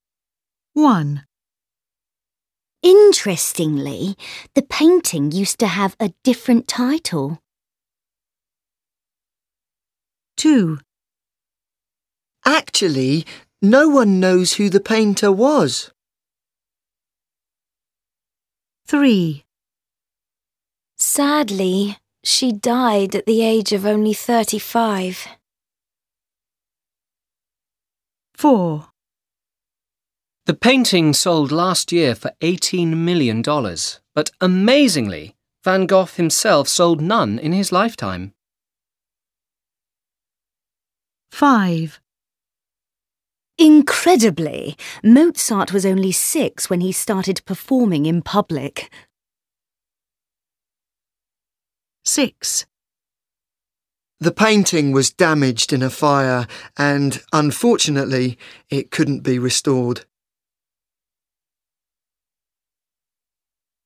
1.10 Pronunciation
Emphasize the stress on the adverbs.